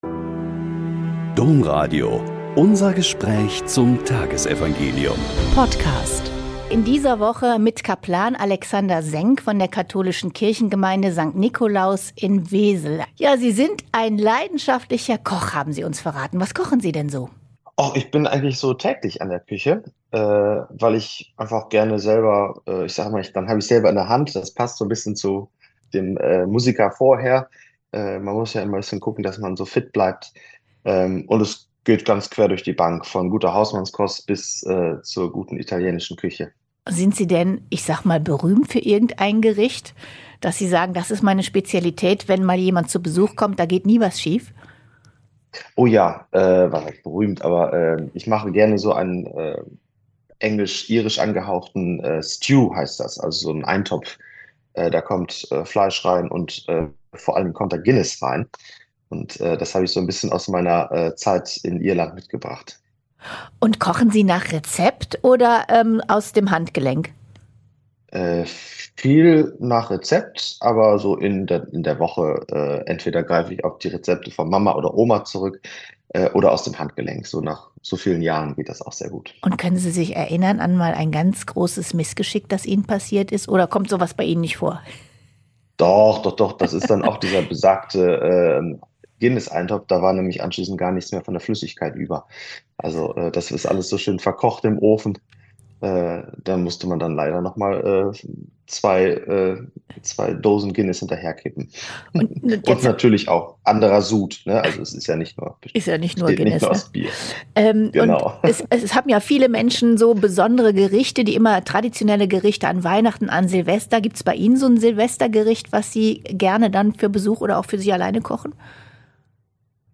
Mt 2,13-18 - Gespräch